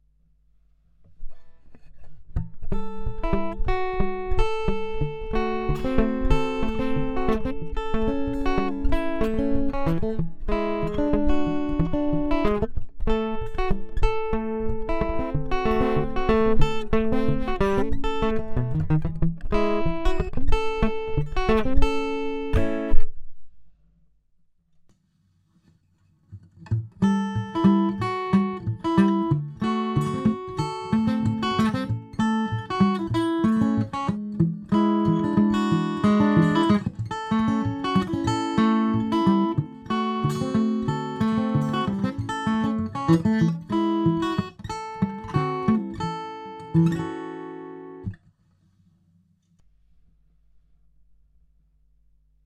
Jeweils gilt: Erste Hälfte-> reiner Pickup-Ton // Zweite Hälfte -> ToneDexter eingeschaltet.
Das muß ich folgendermaßen erläutern: Zum einen ist es schlampig hingenudelter Kram, wie er mir gerade einfiel, unsauber dahingedaddelt.
Die Aufnahmen sind mit dem voll aufgedrehten Effekt erstellt worden.
Die Aufnahmen sind mit dem Line-Out direkt in den Line-Eingang eines Zoom H4n gespielt und komplett unbehandelt. Gitarre: Martin DX1 mit einem an und für sich schon recht gut klingenden K&K pure Western.